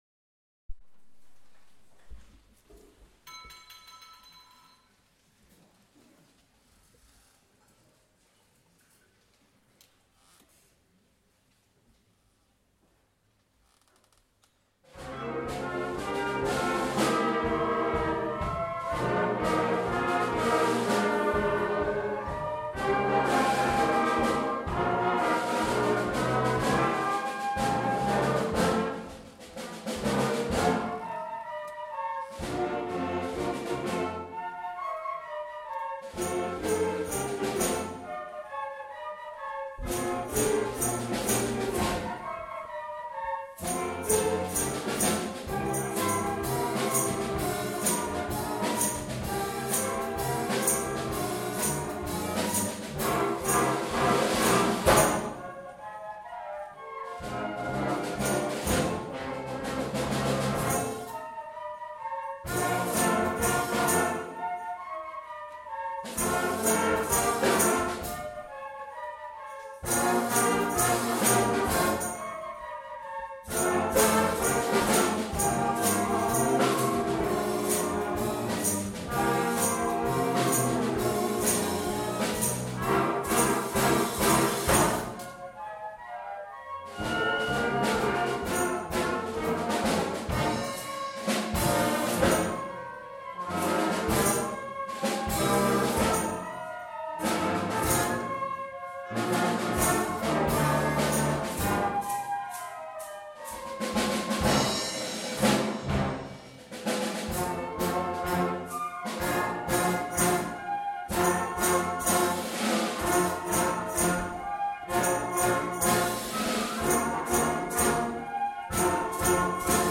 Junior Wind Band - Flute Street
A Concert of Wind, Brass and Percussion, April 2015